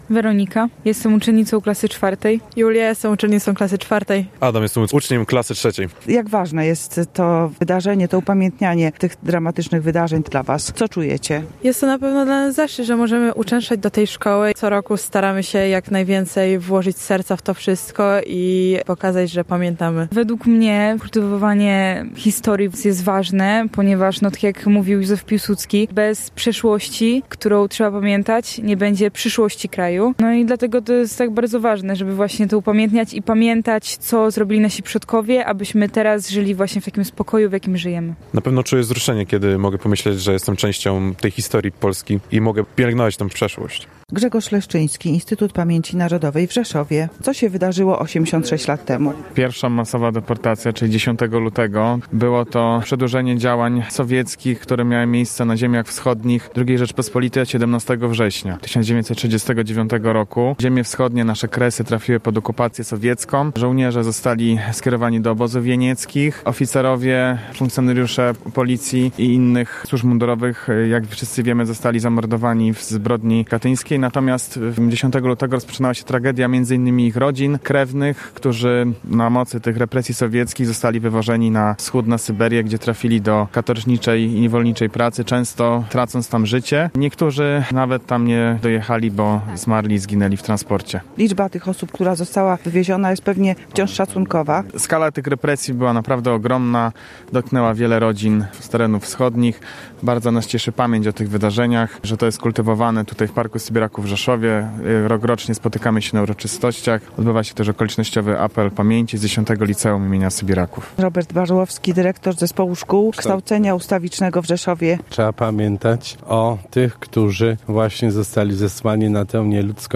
Uroczystość przy Pomniku Sybiraków